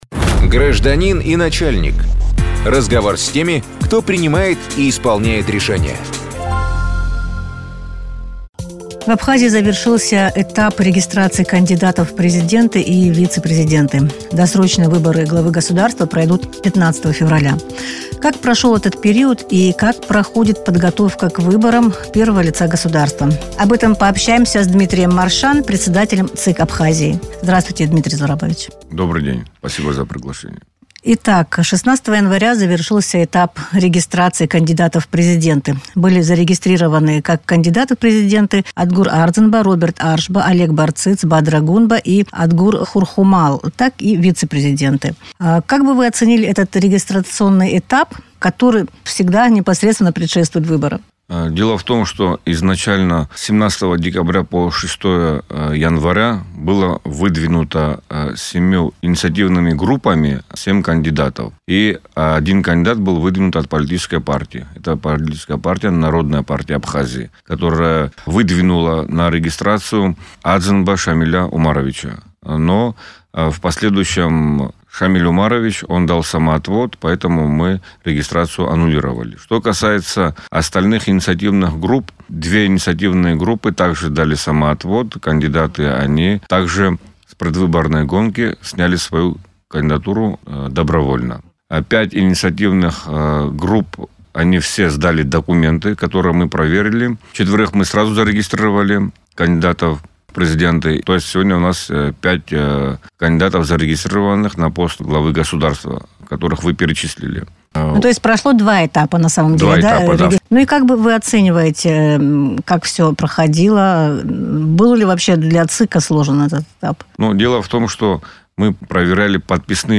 Глава ЦИК Абхазии Дмитрий Маршан в интервью радио Sputnik рассказал о том, как в республике будут проходить выборы президента, какие этапы предвыборной кампании уже завершены.